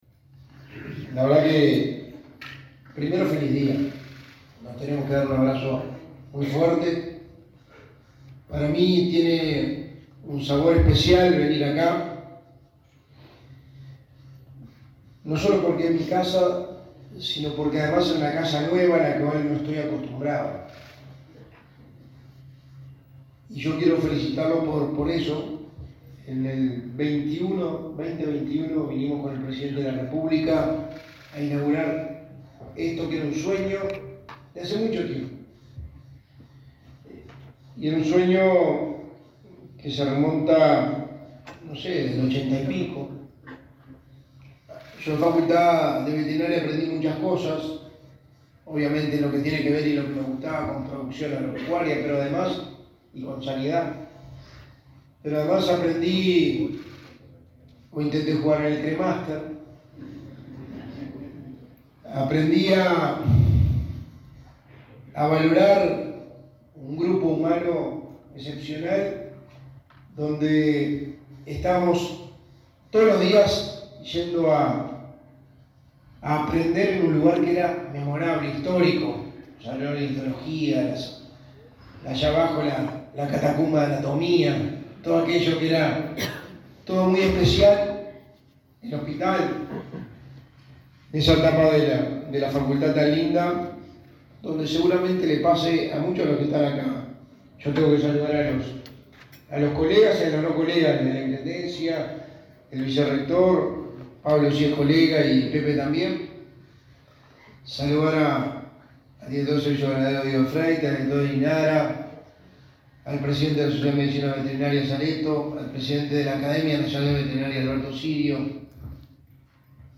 Palabras del secretario de Presidencia, Álvaro Delgado
Este jueves 23 en Montevideo, el secretario de Presidencia de la República, Álvaro Delgado, participó en la ceremonia de conmemoración de los 120 años